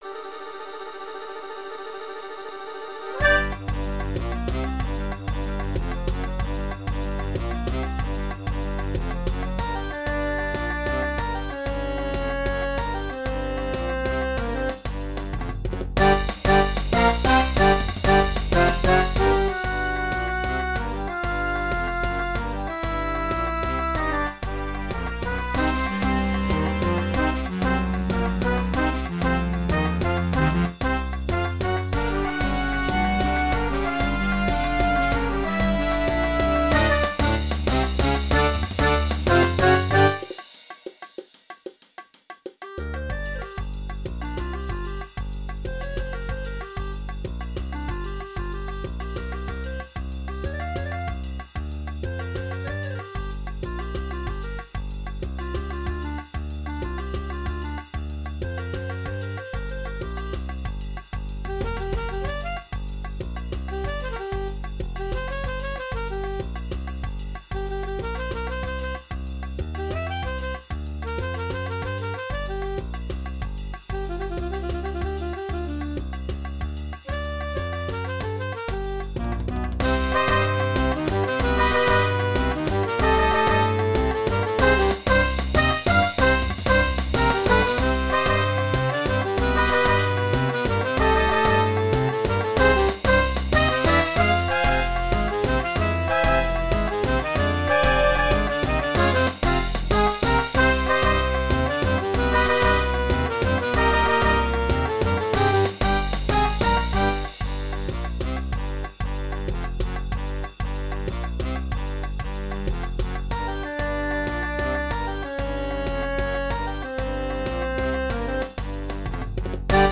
Genre: Musique de film
Enregistrements: Orchestre d'Harmonie